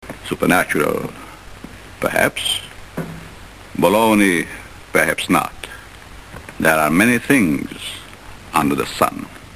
Tags: Bela Lugosi Bela Lugosi sounds Bela Lugosi movie Horror movie audio clips Dracula